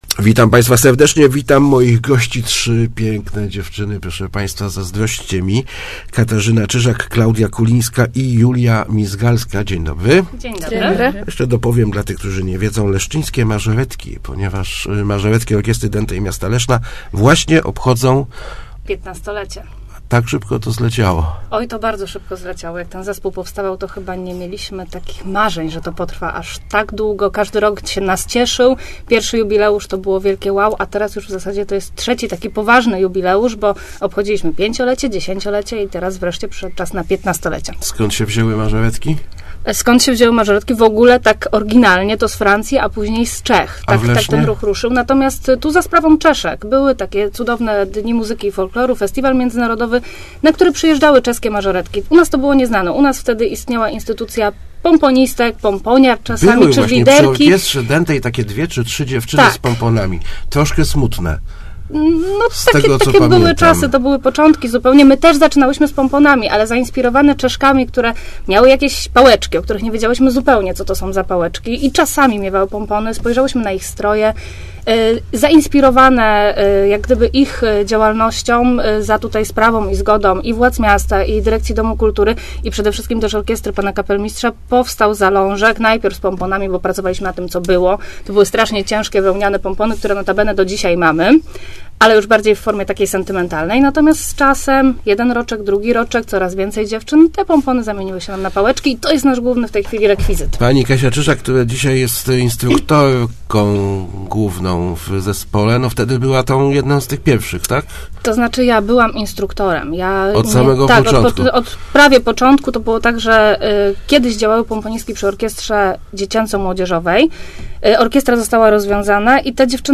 Start arrow Rozmowy Elki arrow 15-lecie leszczyńskich mażoretek